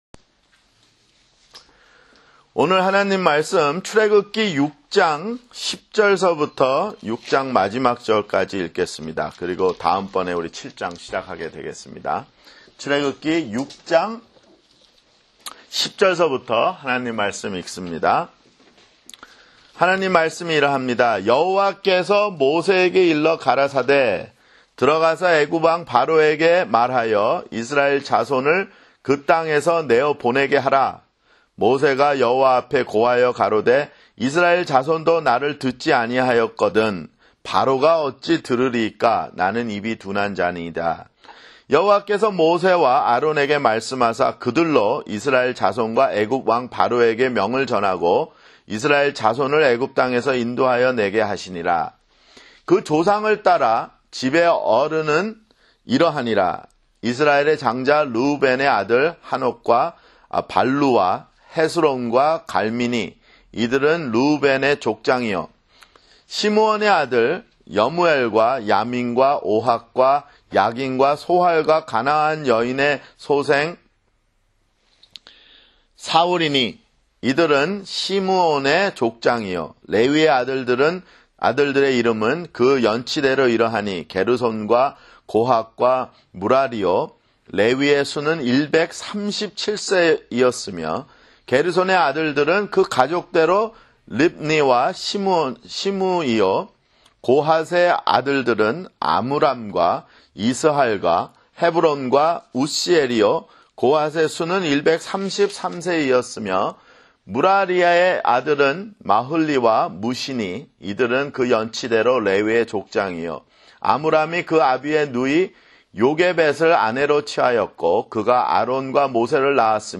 [성경공부] 출애굽기 (10)